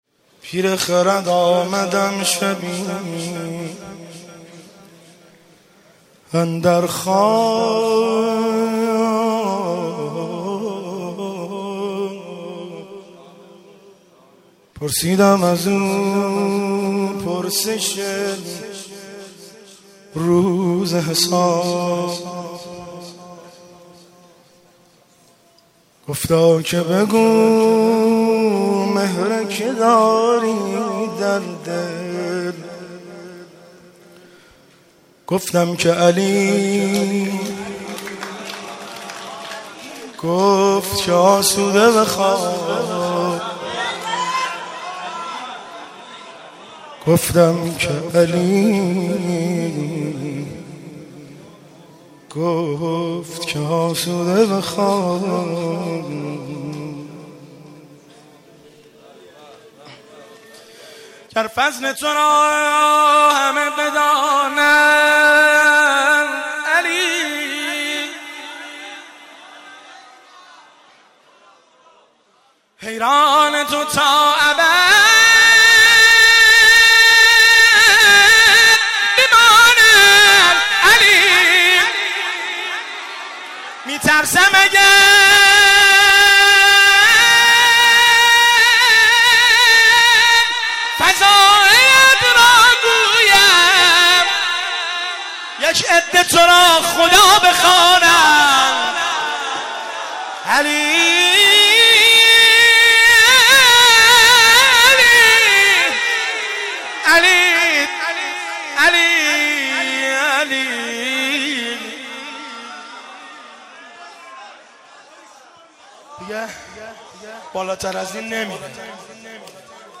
قالب : مناجات